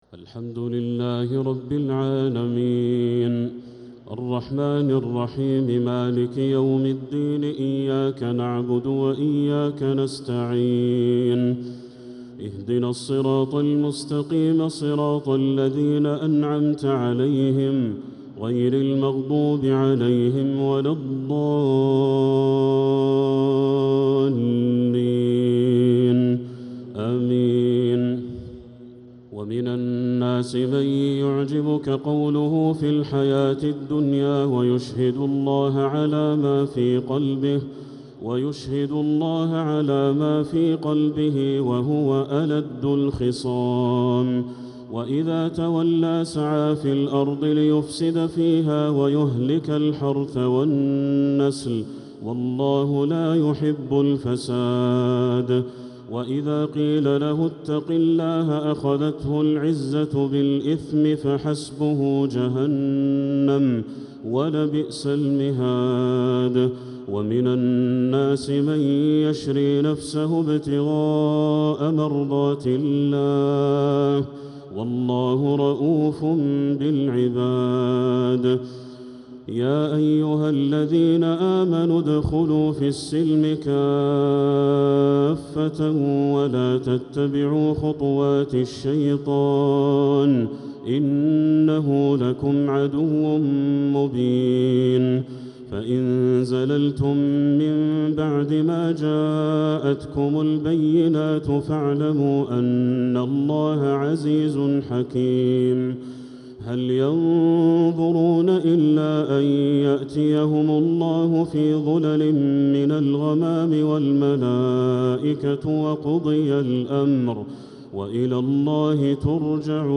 تراويح ليلة 3 رمضان 1446هـ من سورة البقرة {204-248} Taraweeh 3rd night Ramadan 1446H > تراويح الحرم المكي عام 1446 🕋 > التراويح - تلاوات الحرمين